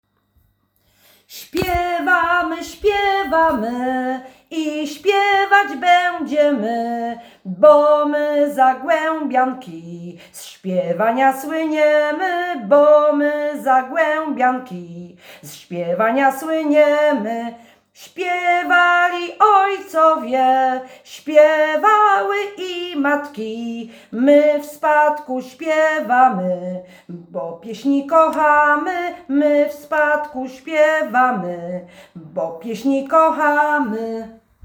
Nagranie współczesne